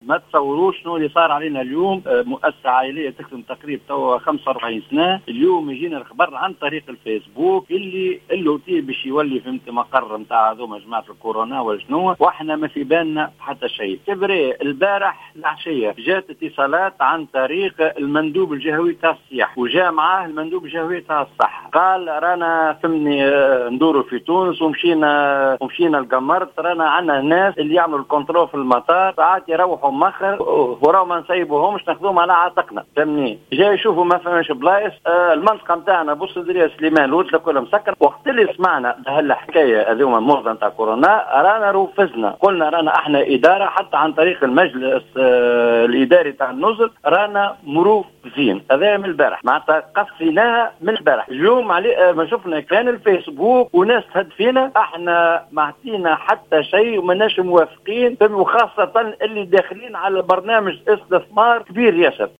في اتصال هاتفي بالجوهرة أف-أم